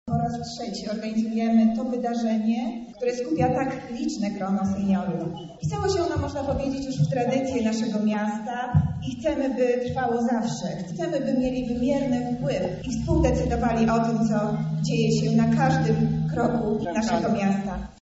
O wydarzeniu mówi Monika Lipińska – wiceprezydent lublina